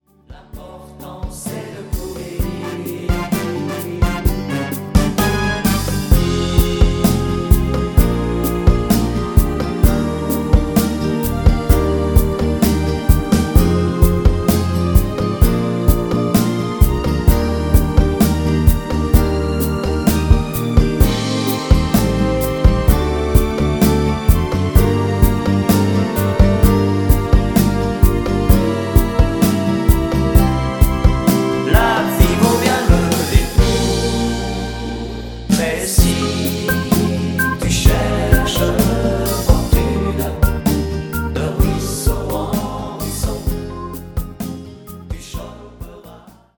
avec choeurs originaux